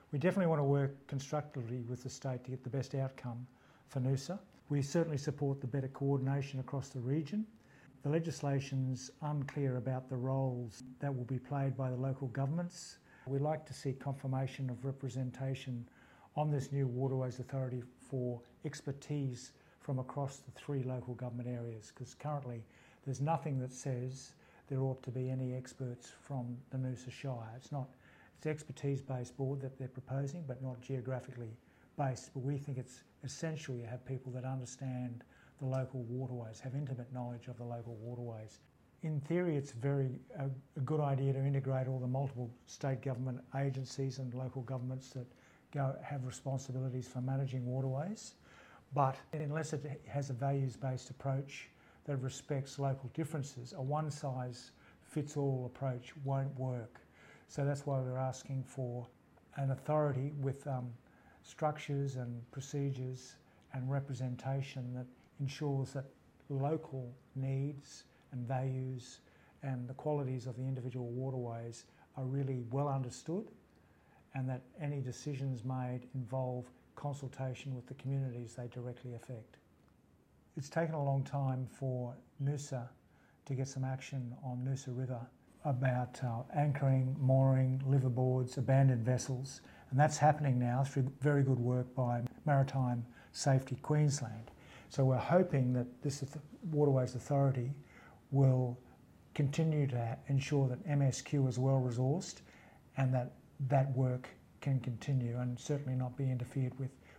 Noosa Mayor Frank Wilkie calls for clarity on the proposed Sunshine Coast Waterway Authority: